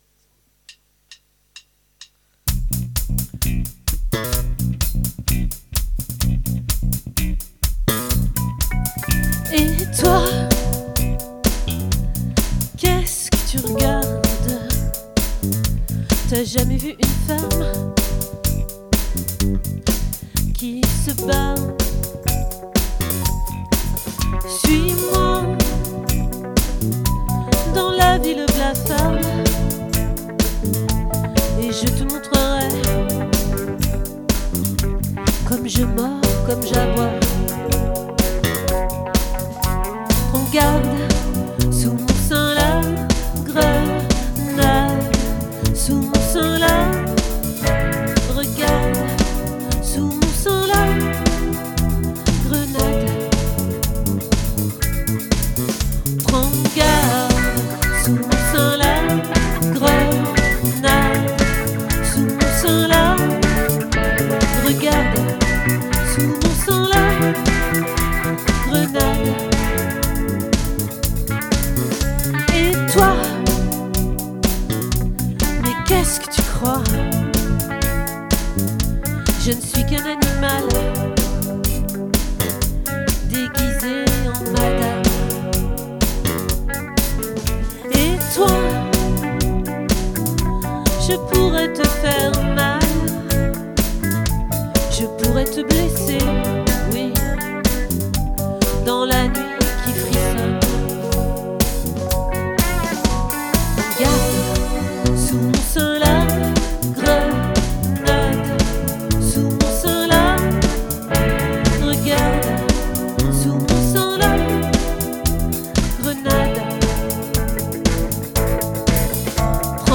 🏠 Accueil Repetitions Records_2025_02_03